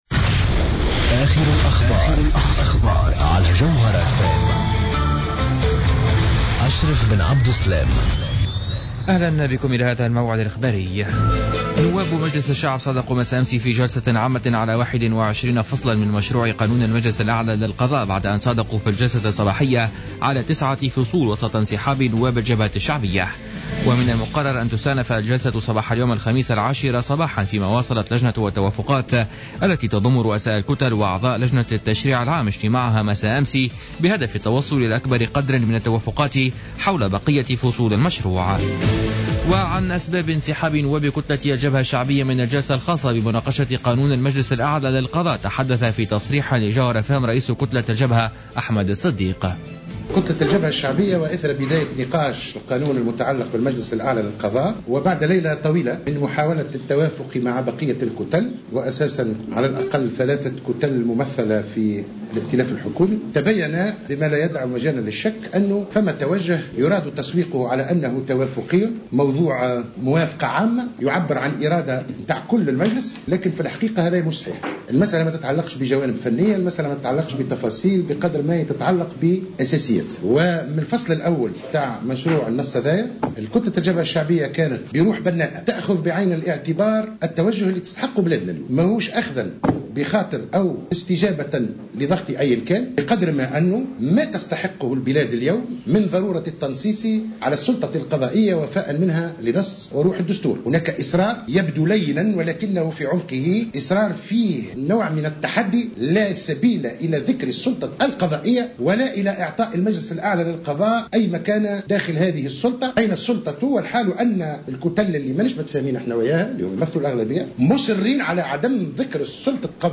نشرة أخبار منتصف الليل ليوم الخميس 14 ماي 2015